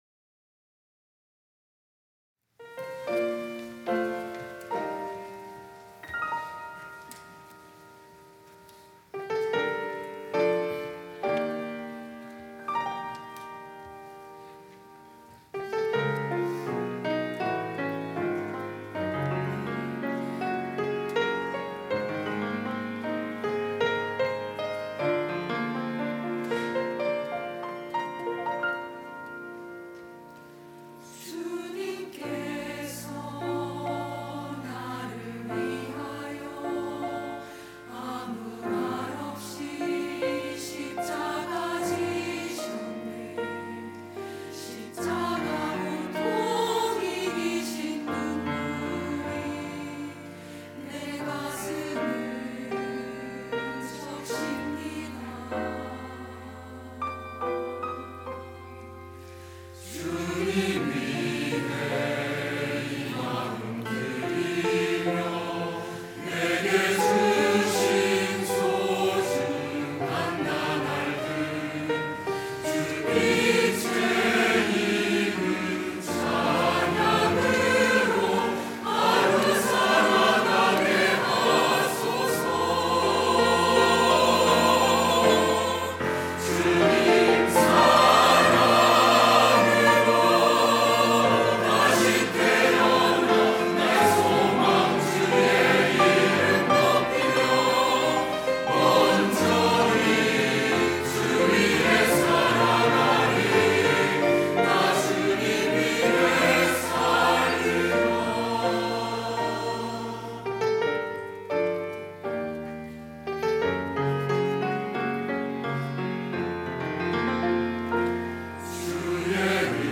할렐루야(주일2부) - 주 위해 살아가리
찬양대 할렐루야